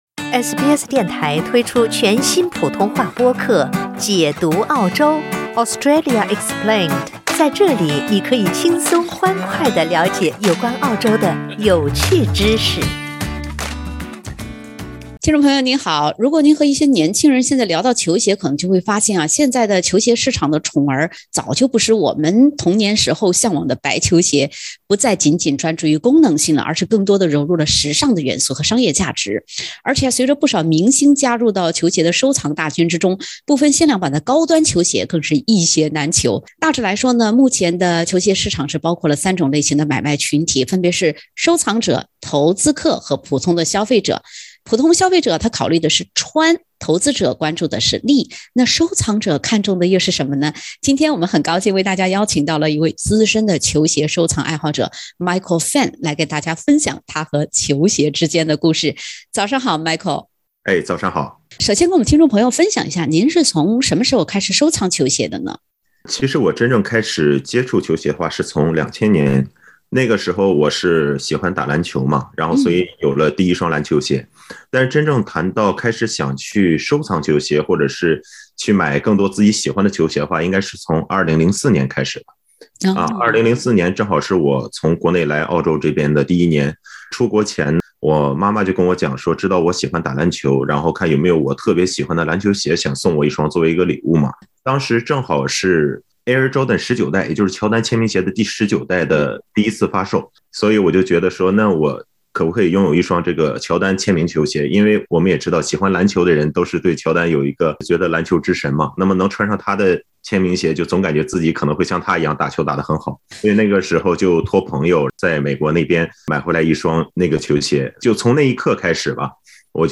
球鞋已经俨然成了如今收藏市场和“潮人们”的新宠。(点击封面图片，收听有趣对话）